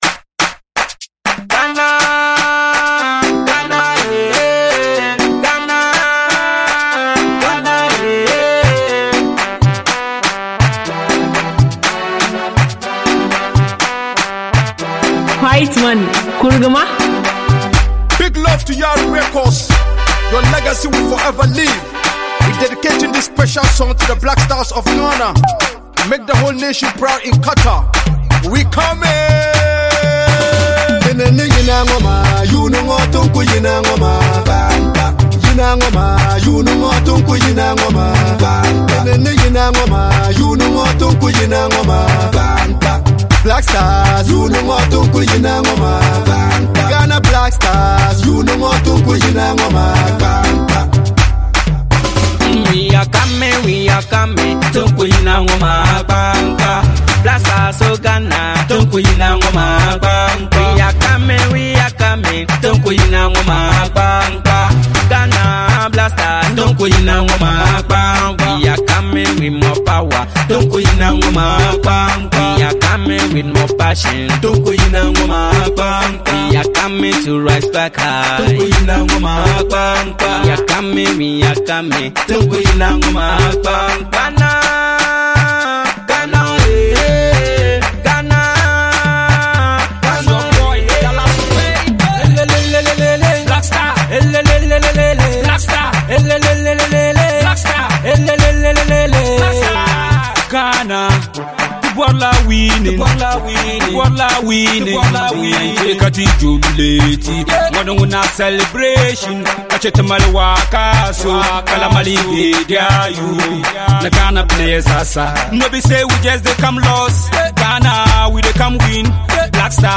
With its vibrant rhythm and inspiring message